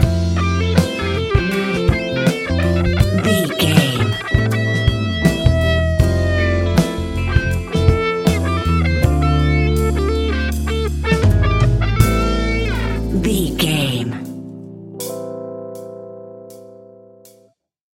Epic / Action
Fast paced
In-crescendo
Uplifting
Ionian/Major
A♭
hip hop